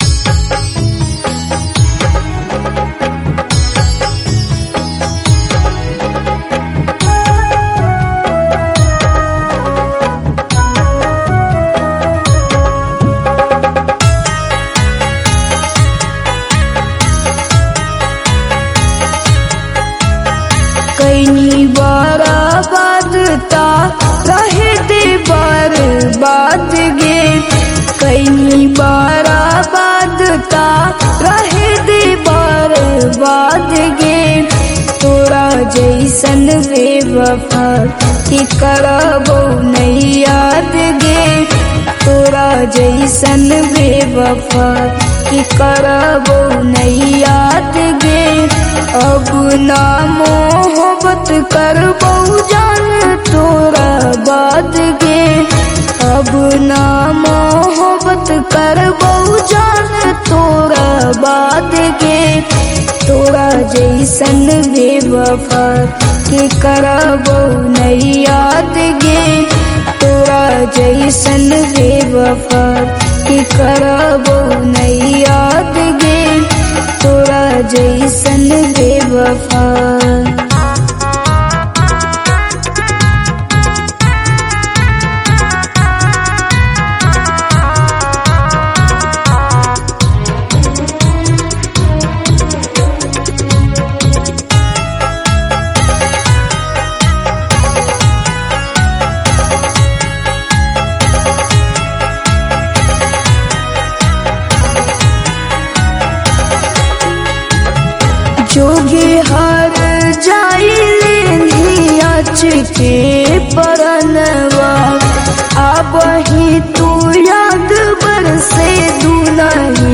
Category: Bhojpuri